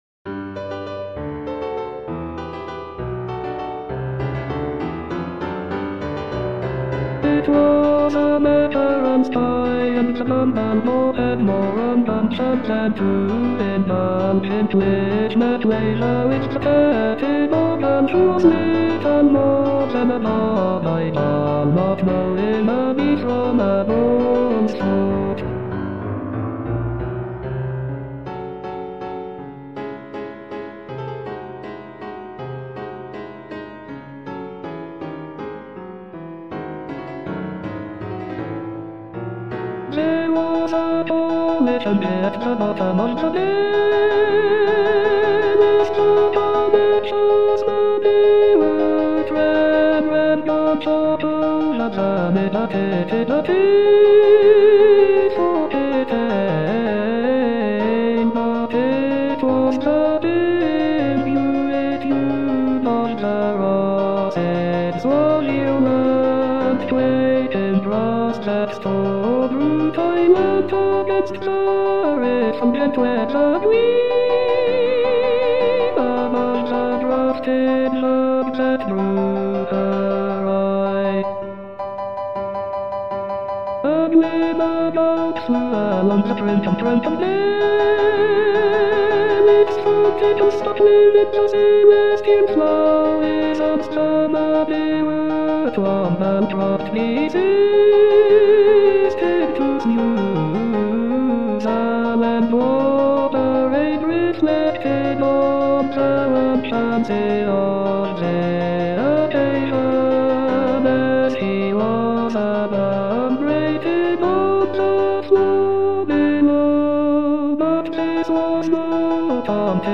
Number of voices: 1v Voicing: S Genre
Language: English Instruments: Piano